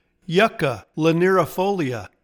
Pronounciation:
YUK-ka li-near-i-FOL-ee-a